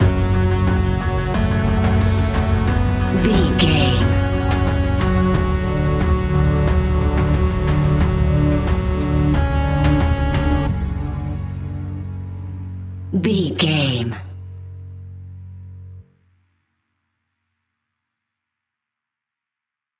Aeolian/Minor
F#
ominous
dark
haunting
eerie
industrial
drums
synthesiser
horror music